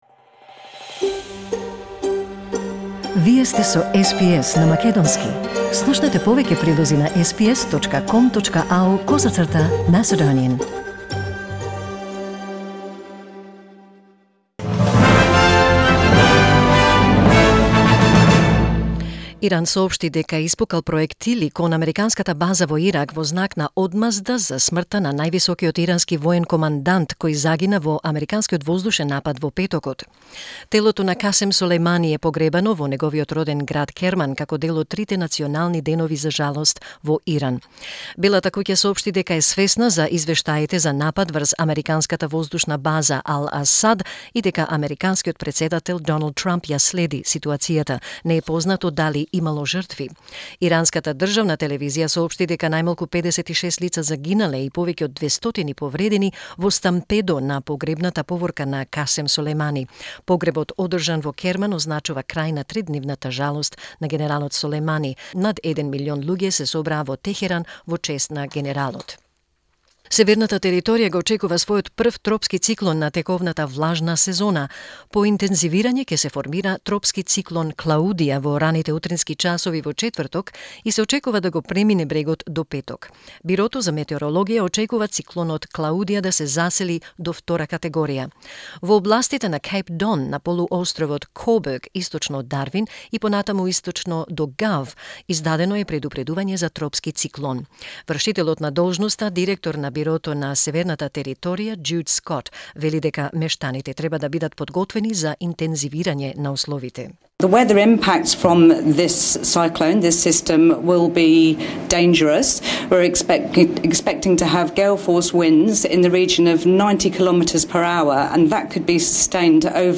SBS News in Macedonian 8 January 2020